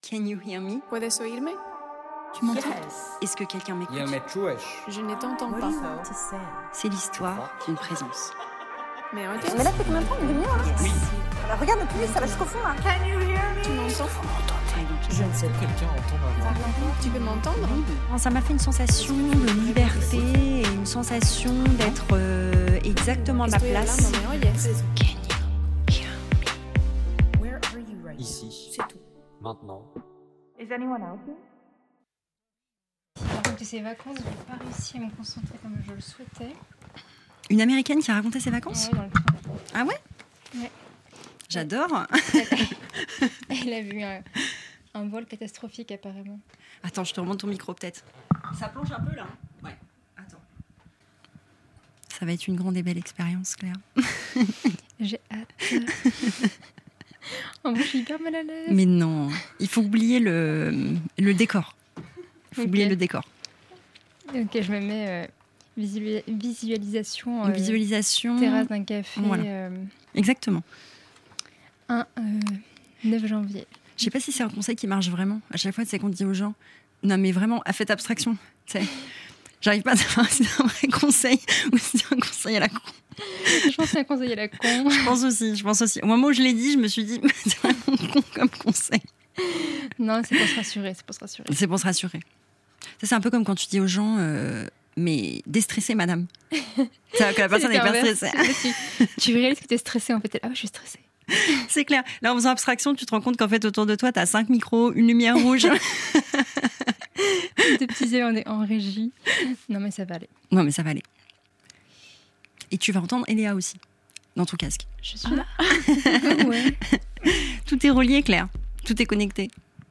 Just a conversation about trying, failing, adjusting, and staying present.